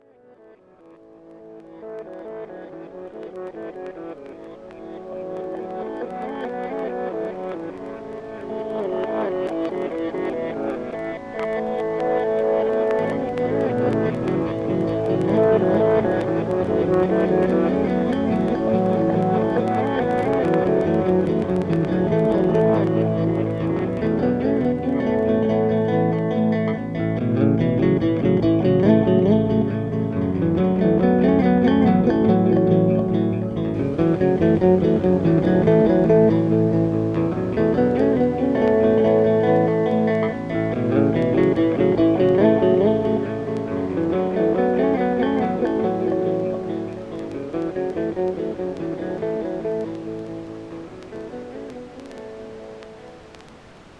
The crickets are fantastic in Georgia this time of year.